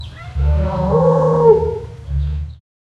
Nighttime Roaring Chorus Bouton sonore